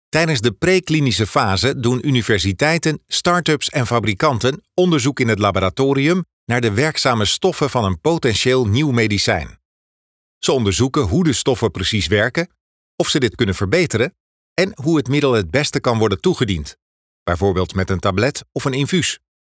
Naturelle, Accessible, Amicale, Corporative
E-learning